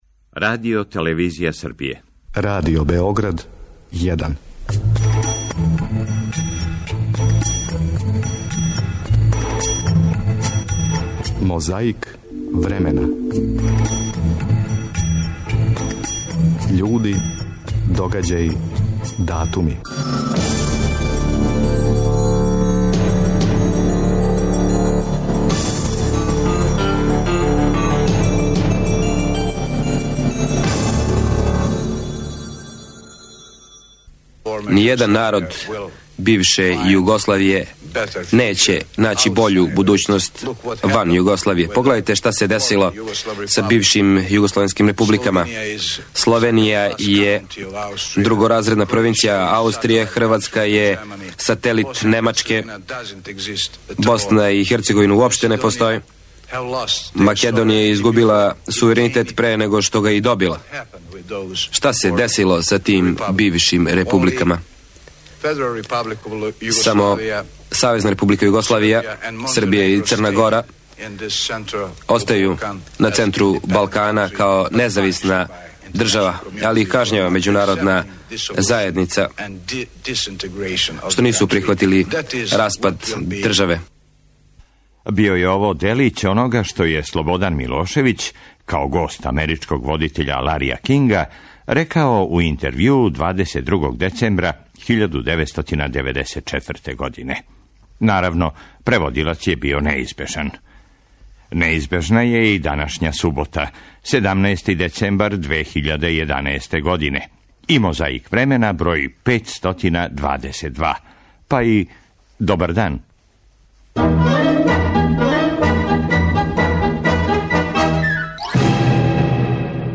Давне 1994. године гост Ларија Кинга био је председник Србије Слободан Милошевић. Повод је био нов прекид ватре у БиХ који је договорио Џими Картер, а Милошевић је дао врло добар интервју, говорећи како Србија чини све да се мир врати у Босну.